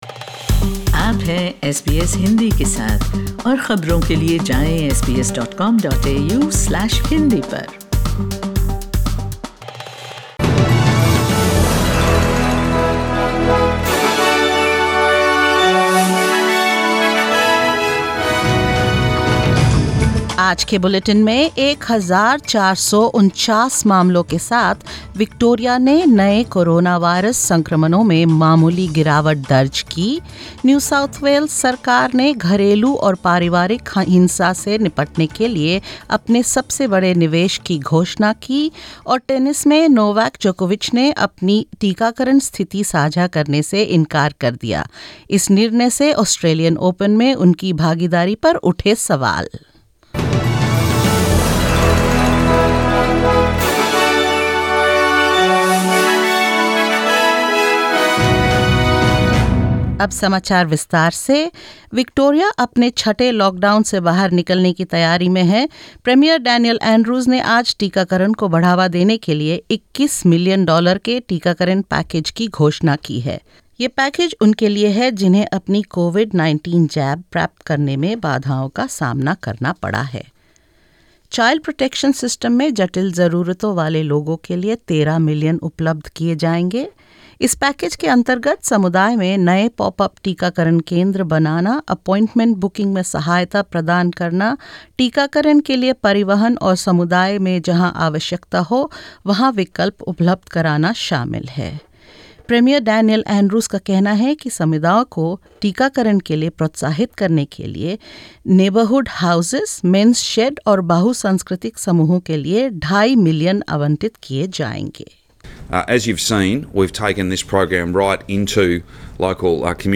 In this latest SBS Hindi News bulletin of Australia and India: Victorian Premier Daniel Andrews announces 21 million dollars to encourage communities facing barriers to get vaccinated; New South Wales to invest almost $500 million to address domestic and family violence in the state; Novak Djokovic declines to reveal his COVID-19 vaccination status casting doubts on his involvement in the Australian Open and more.